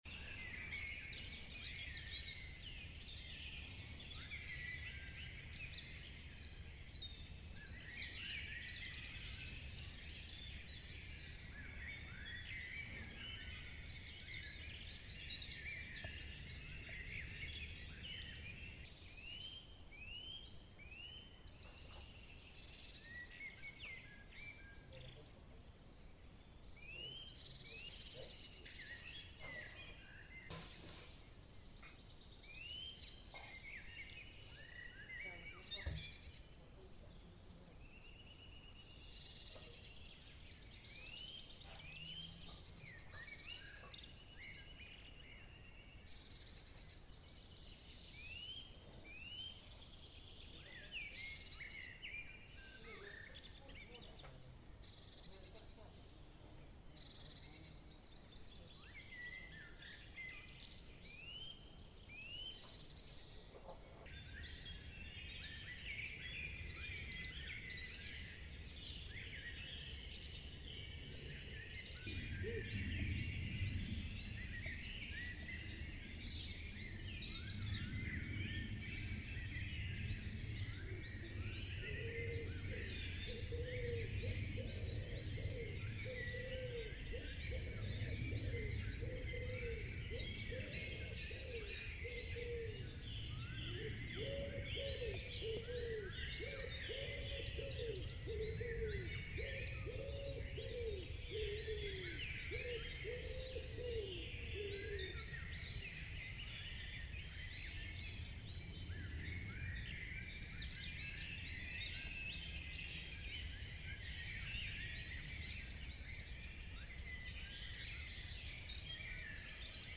luister naar de vogels
vogels.mp3